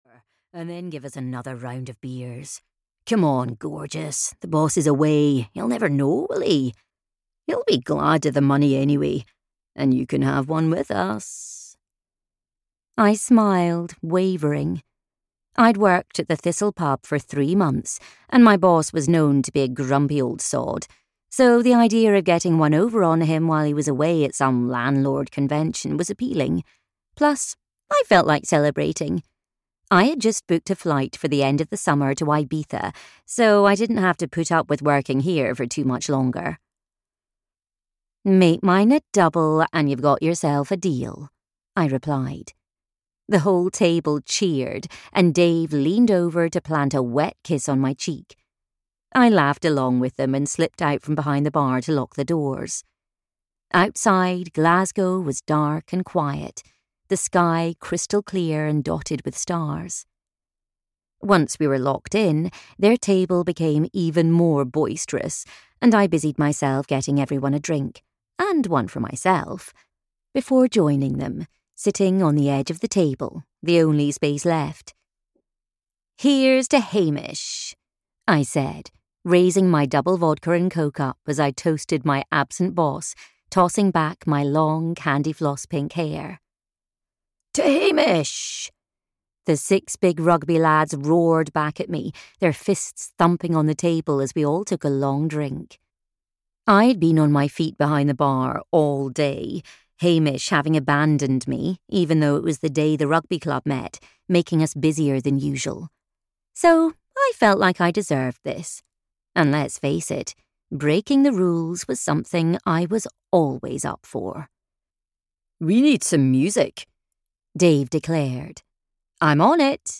Always and Forever at Glendale Hall (EN) audiokniha
Ukázka z knihy